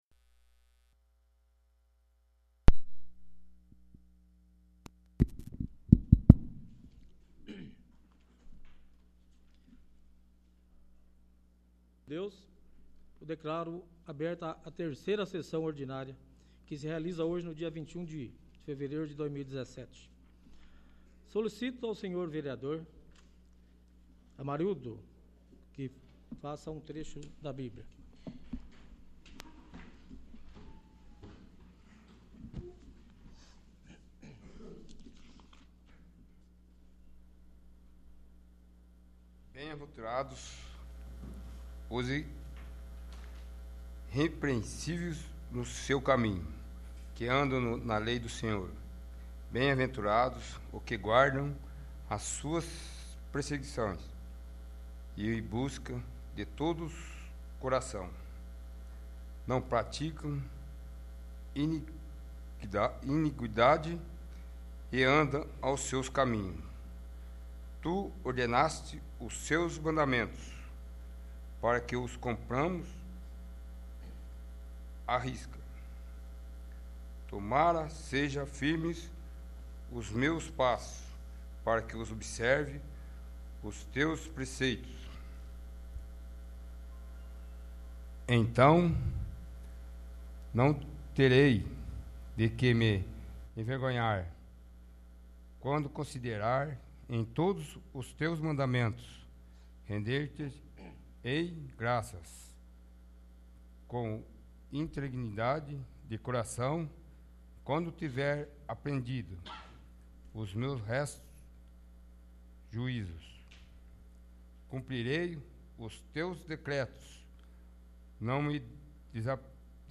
3º. Sessão Ordinária